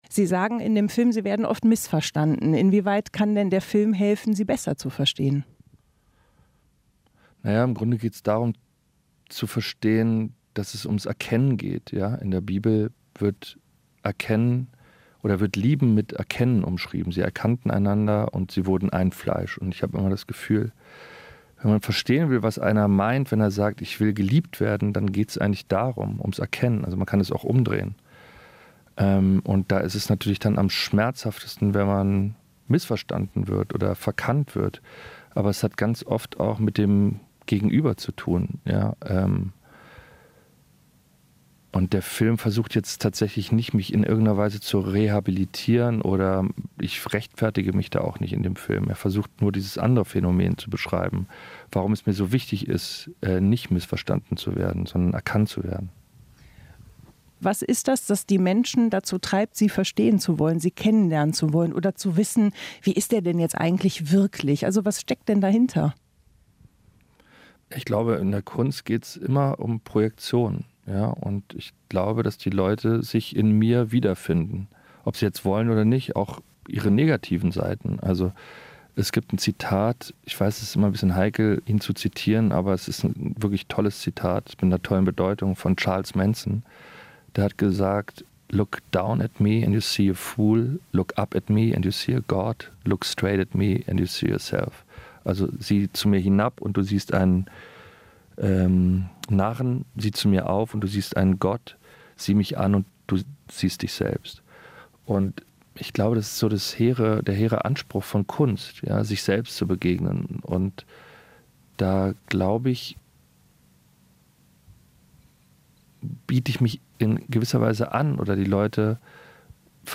lars_eidinger_interview-v2.mp3